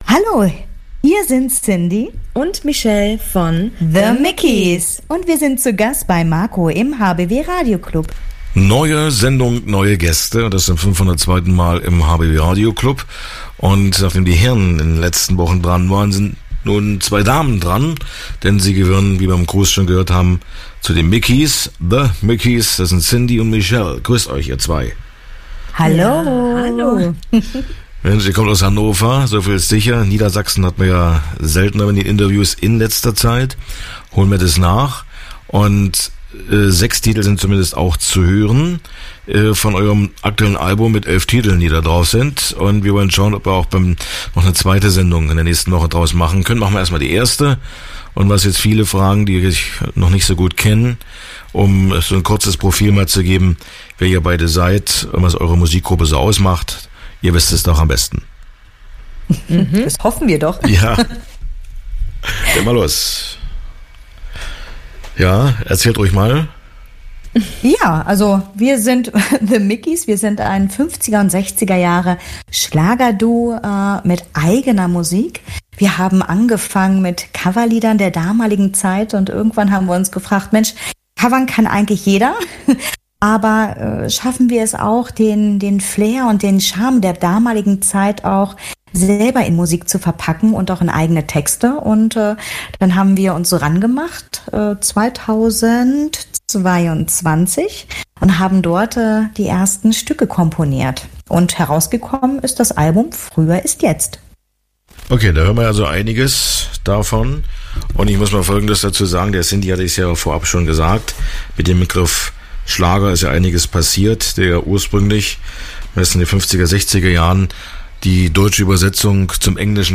Mit Musik der 50er und 60er Jahre versprühen sie gute Laune und ein Gefühl von heiler Welt. Ihr Repertoire umfasst deutsche Schlager und englische Evergreens.